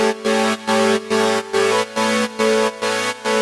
Index of /musicradar/sidechained-samples/140bpm
GnS_Pad-MiscA1:4_140-A.wav